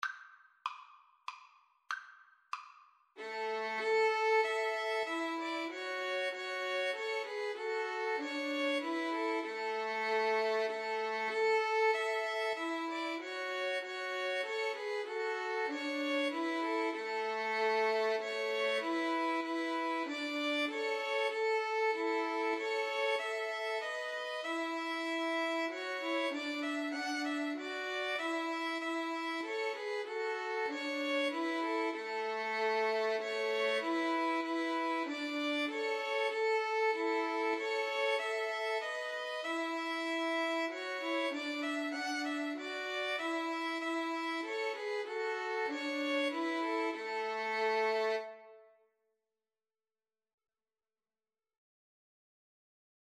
Free Sheet music for Violin Trio
Violin 1Violin 2Violin 3
A major (Sounding Pitch) (View more A major Music for Violin Trio )
3/4 (View more 3/4 Music)
Andante expressivo = c. 96
Classical (View more Classical Violin Trio Music)